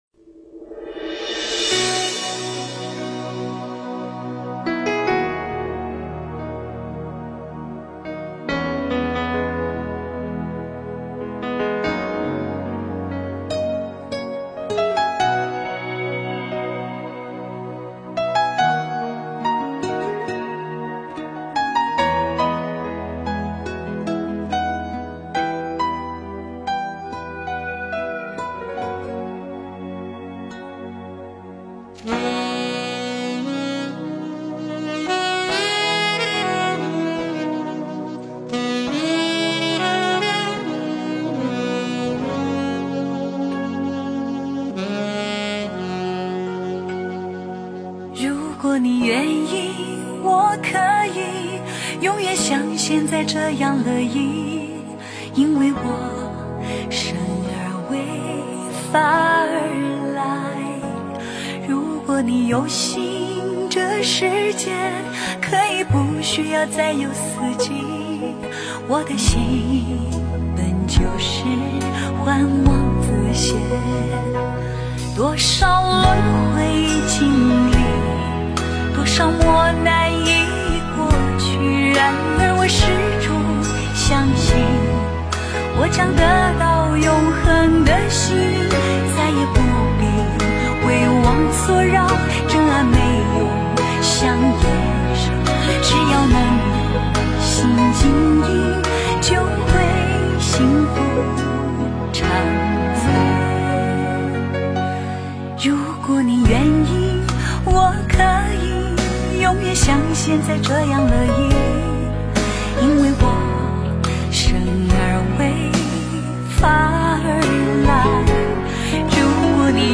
佛教音乐